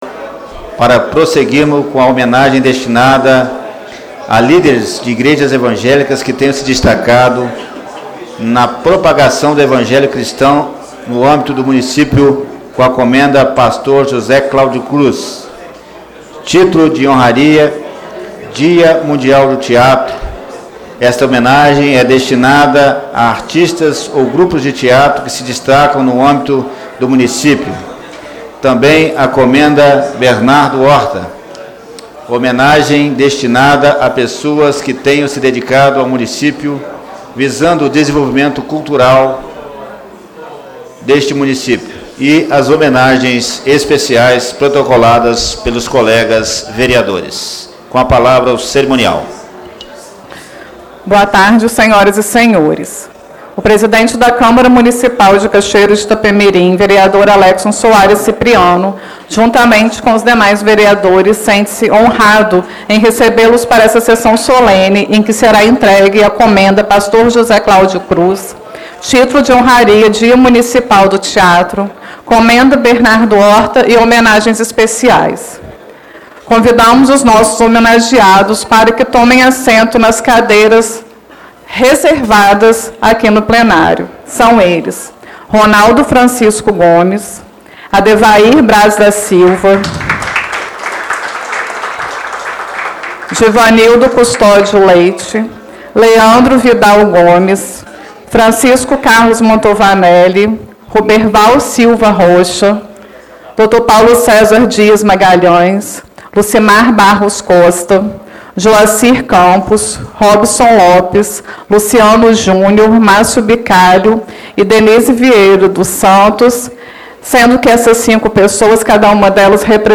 Áudio da Sessão Solene 02 de 25/03/2019